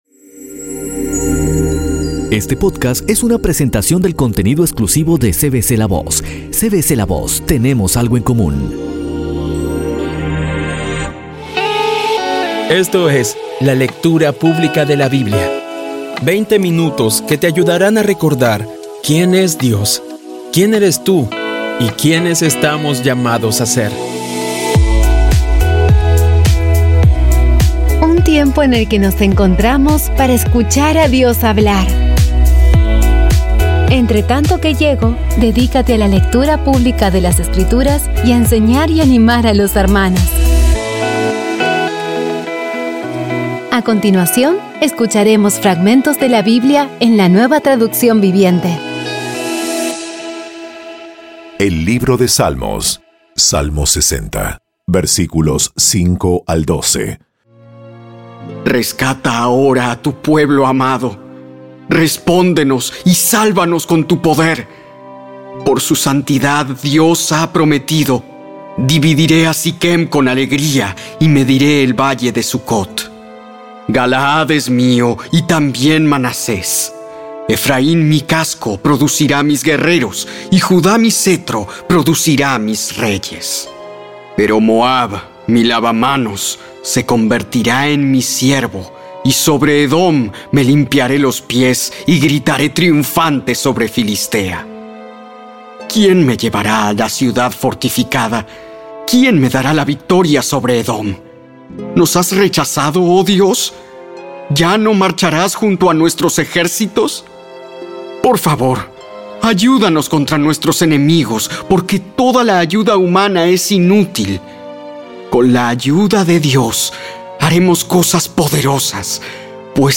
Audio Biblia Dramatizada Episodio 133
Poco a poco y con las maravillosas voces actuadas de los protagonistas vas degustando las palabras de esa guía que Dios nos dio.